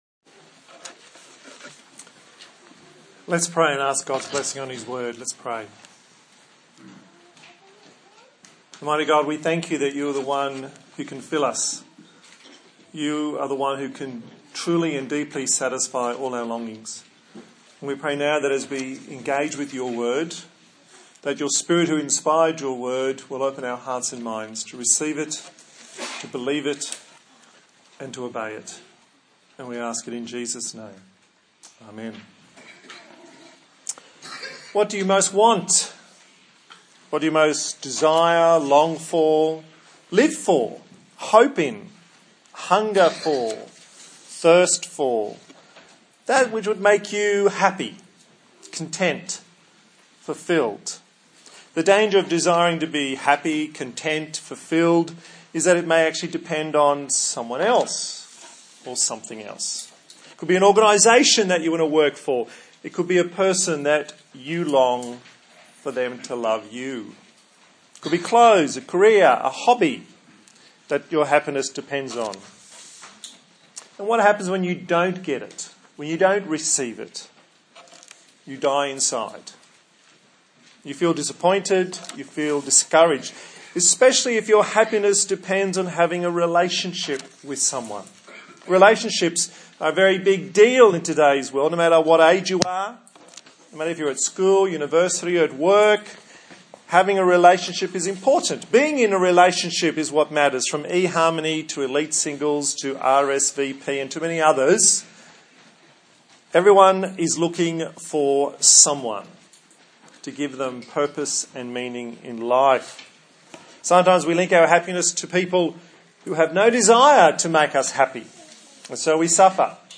A sermon in the series on the book of John
Service Type: Sunday Morning